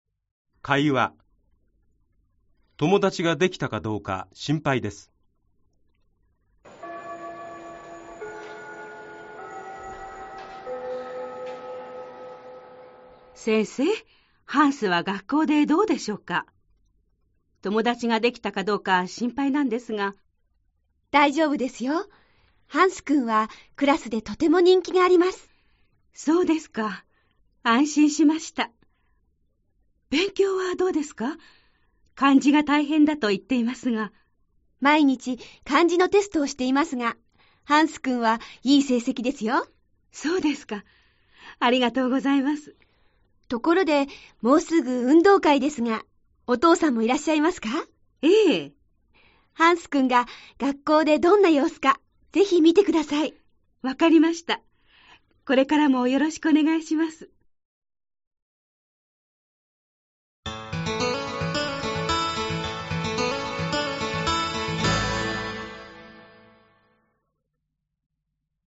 4. Kaiwa
40-4-Kaiwa.mp3